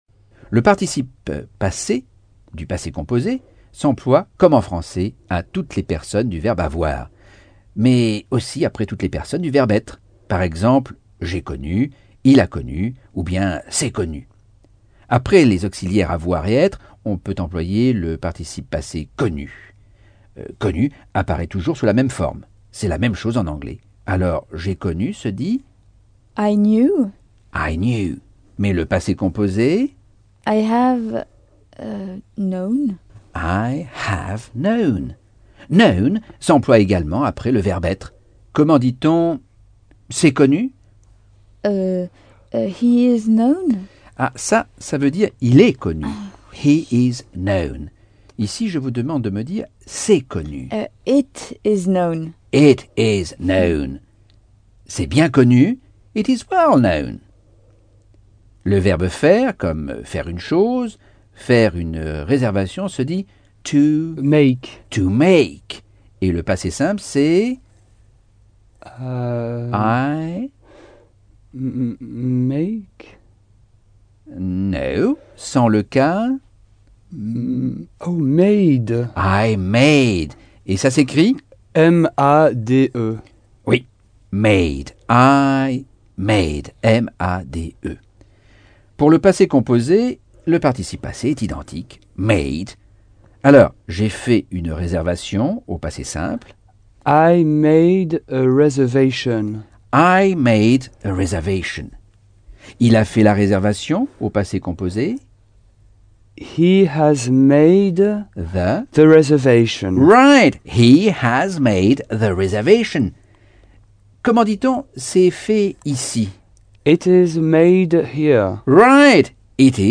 Leçon 9 - Cours audio Anglais par Michel Thomas - Chapitre 8